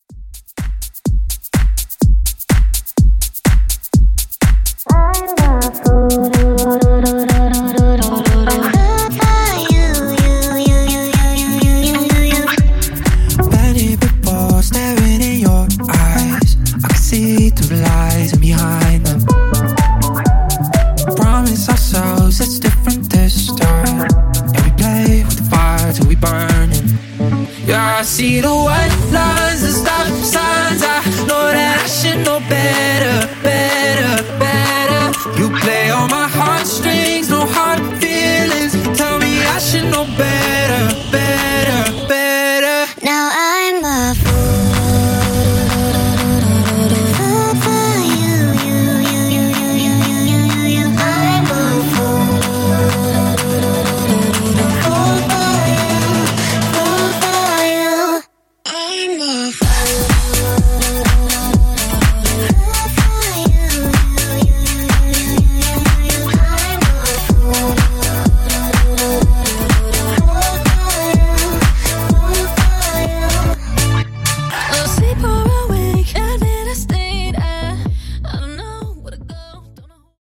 Club Extended Mix)Date Added